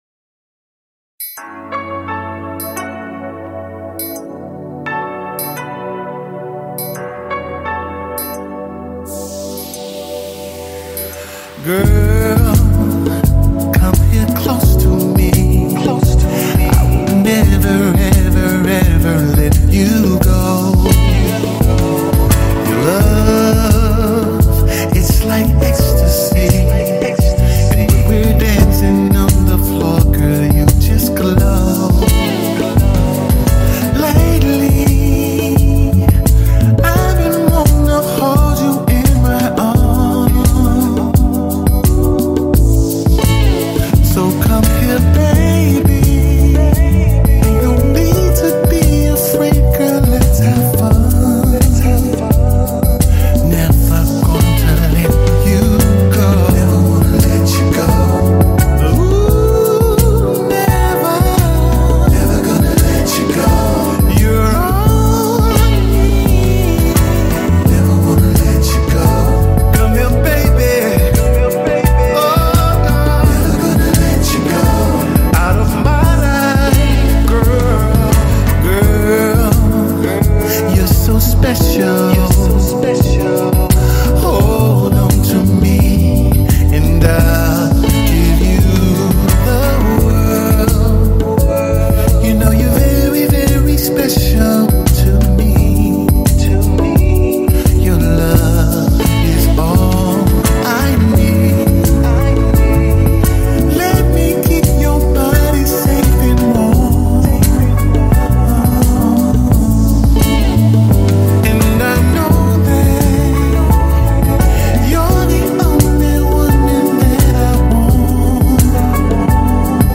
making him a true pillar in R&B and soul music.